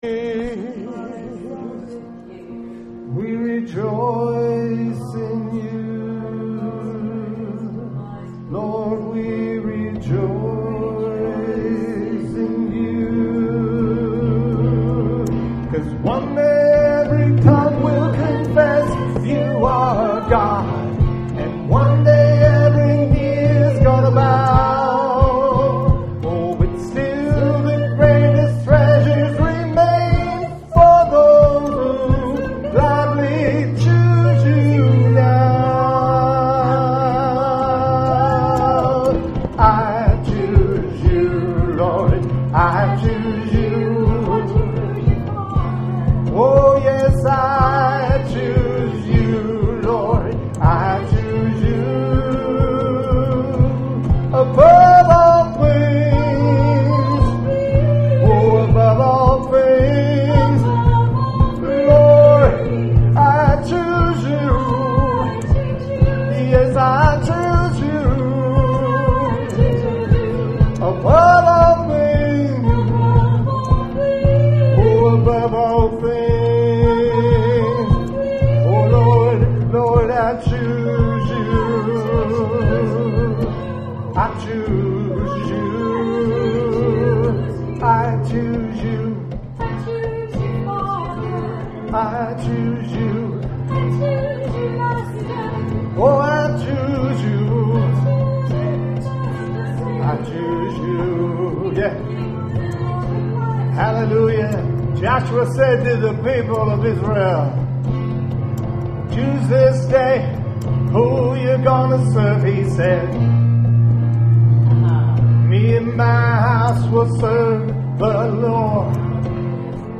WORSHIP 713.mp3